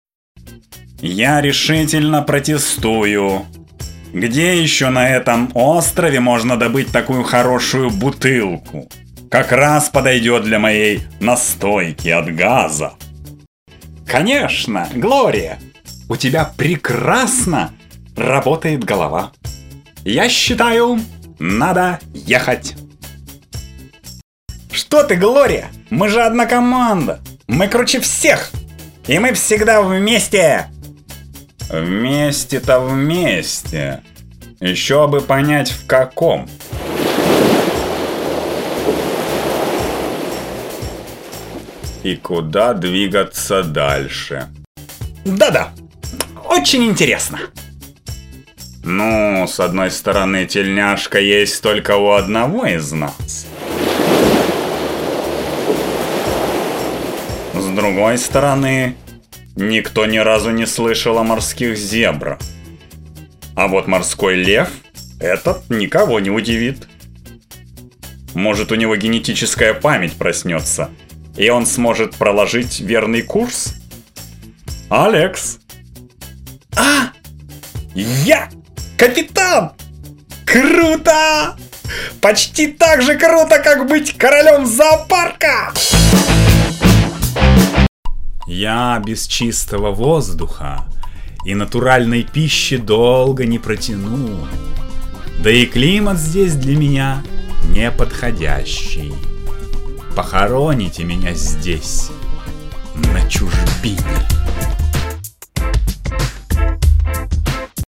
Mикрофон Октава МК-105, предусилитель ламповый ART Tube Amp, предусилитель dbx286a, карточка Avid Mbox, Pro Tools 8, MacBook Pro.
Демо-запись №1 Скачать